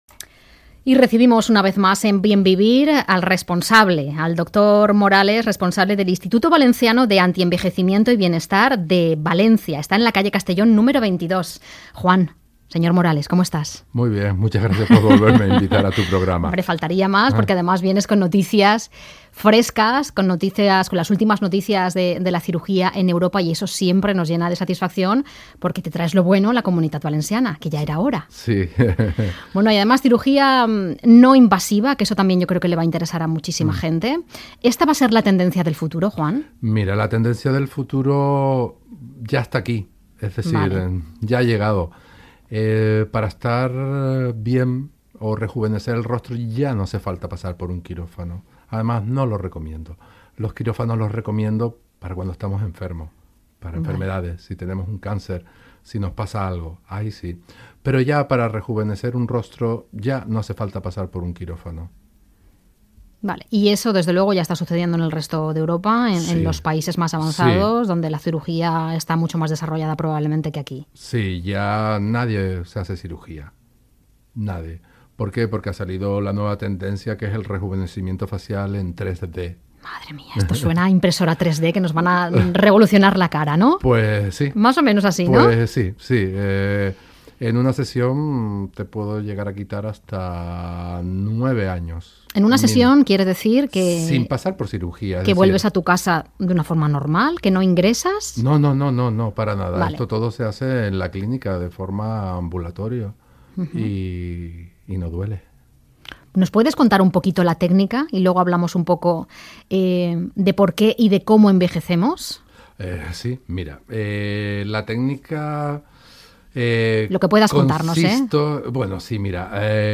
IVAB en Cadena Ser presentando el novedoso rejuvenecimiento facial sin cirugía
IVAB-CADENA-SER.mp3